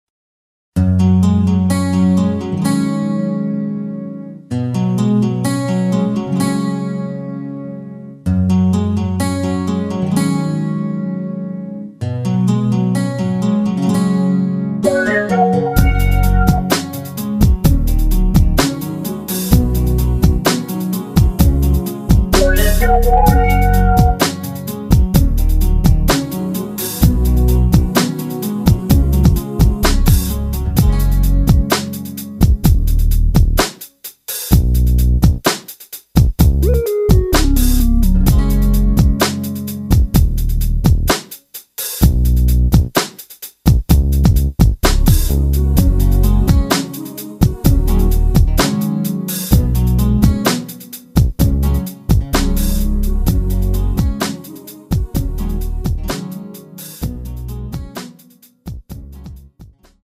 원키에서(-1)내린 MR입니다.
F#
앞부분30초, 뒷부분30초씩 편집해서 올려 드리고 있습니다.
중간에 음이 끈어지고 다시 나오는 이유는